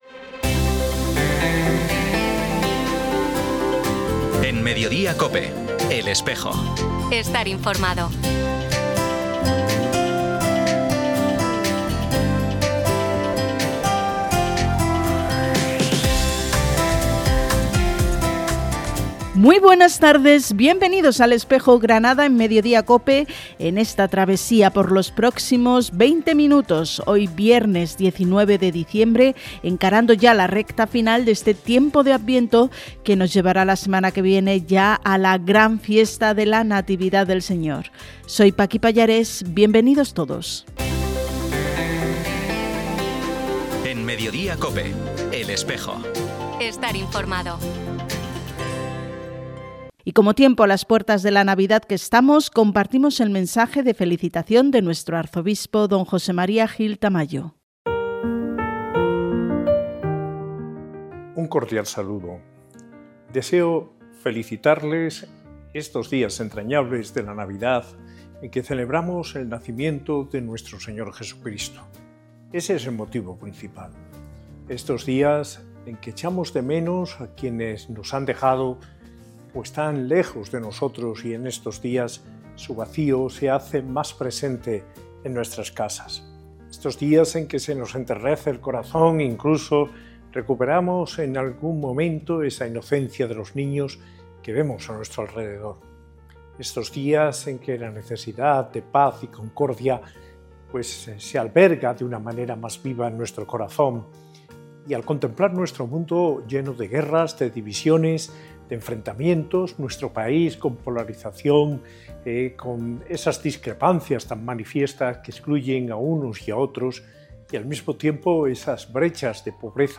Emitido hoy viernes 19 de diciembre de 2025, en COPE Granada.
También escuchamos la felicitación navideña y mensaje de nuestro arzobispo Mons. José María Gil Tamayo, entre otros contenidos.